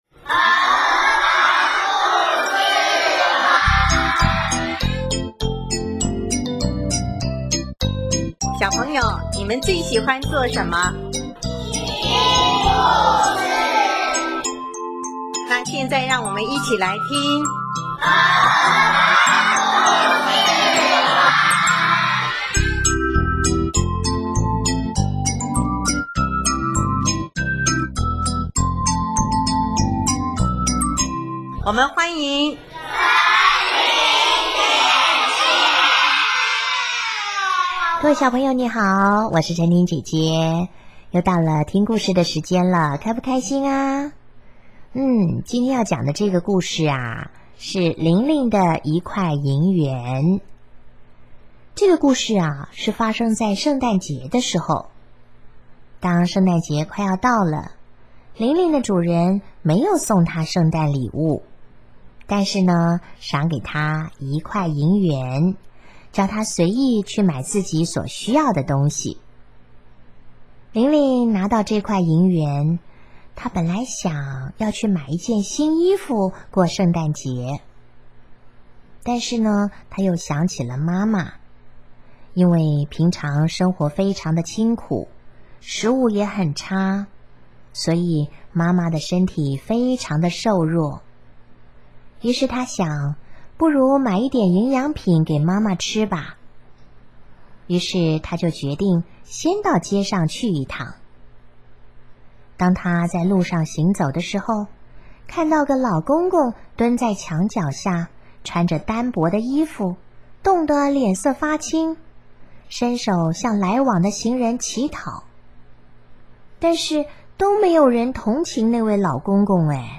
首页 / 家庭/ 儿童故事